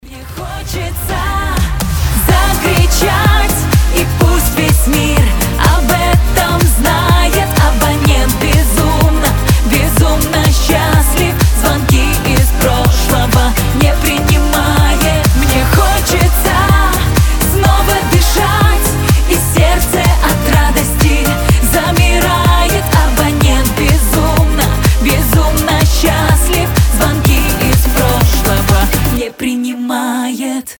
громкие
женский голос